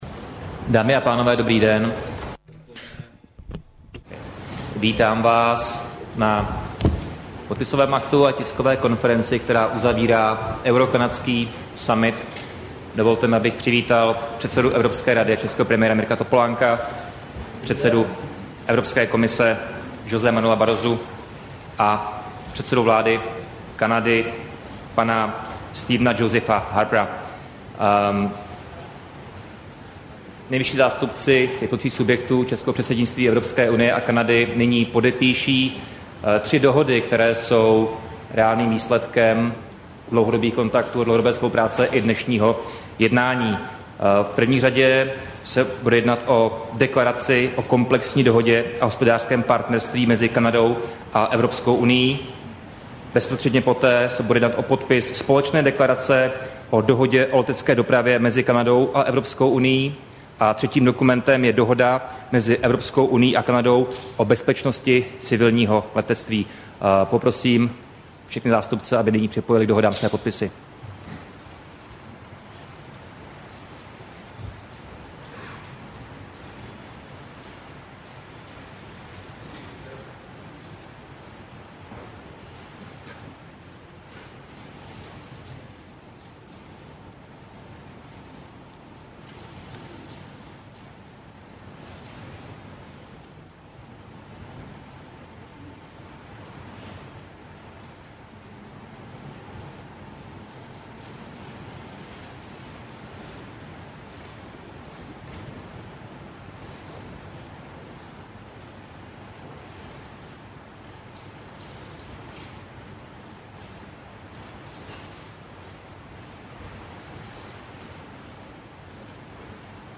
Tisková konference po summitu EU - Kanada, 6. května 2009